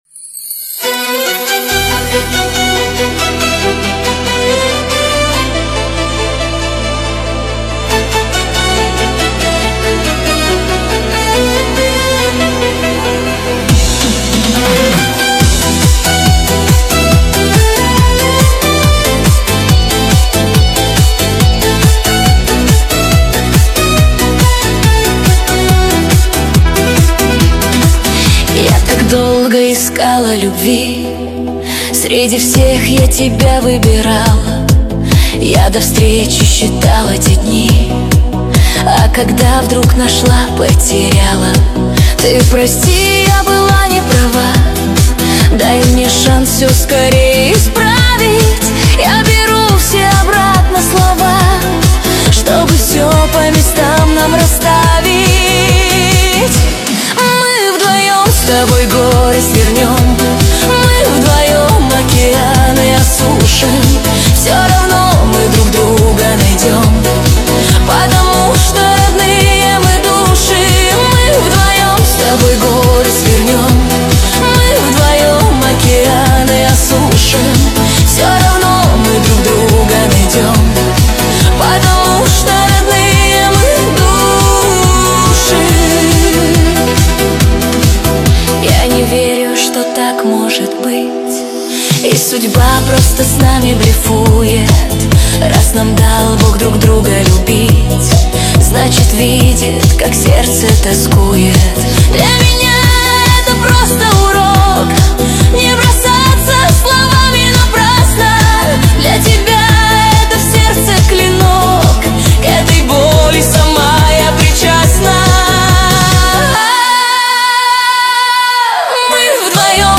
13 декабрь 2025 Русская AI музыка 72 прослушиваний